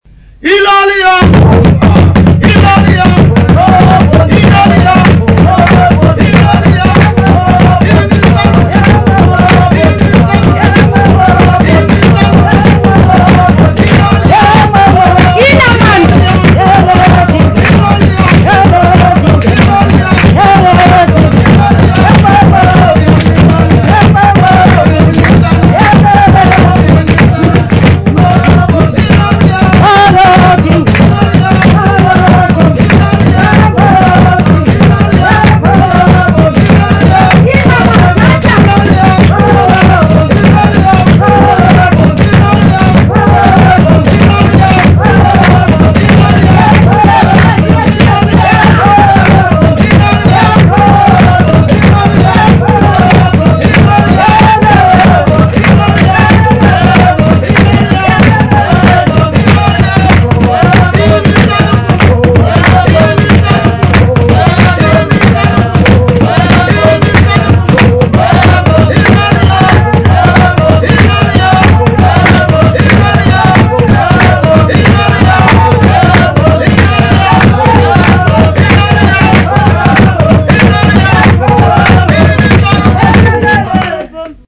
Group of Xhosa men
Folk music
Field recordings
Africa South Africa Nqoko sa
Traditional Xhosa song, with drums accompaniment
96000Hz 24Bit Stereo